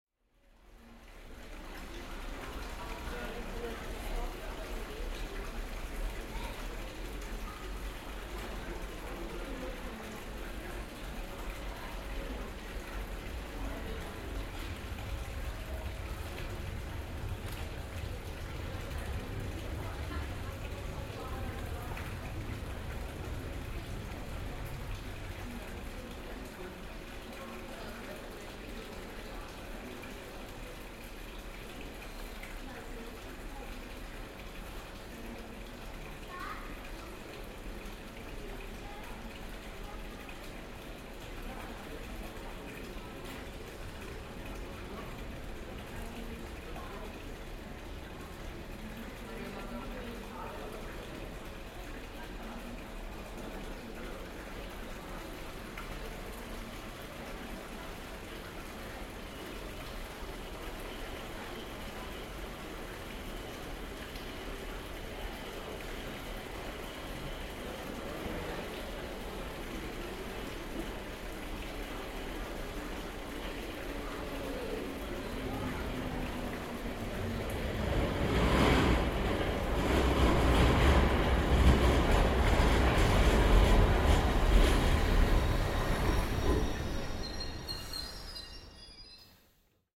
Running water in Victoria tube station
Field recording from the London Underground by The London Sound Survey.